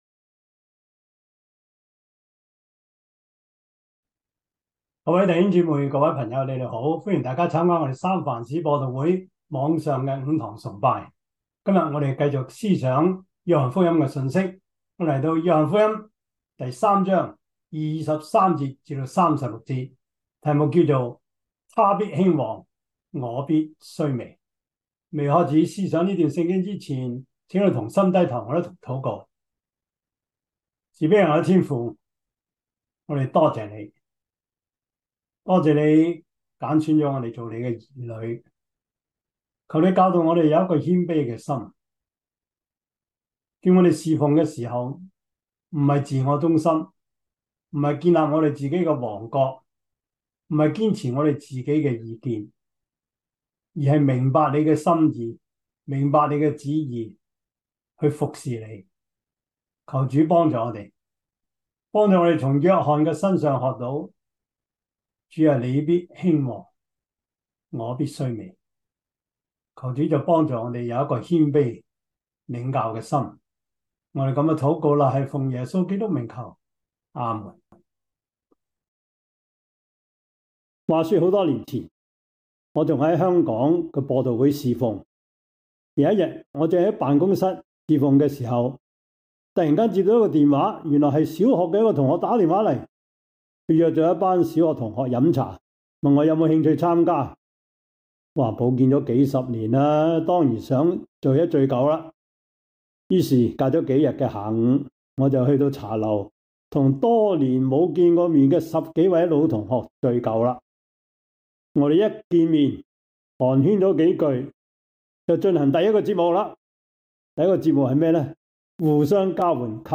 約翰福音 3:22-36 Service Type: 主日崇拜 約翰福音 3:22-36 Chinese Union Version
」 Topics: 主日證道 « 你的恩賜用多少？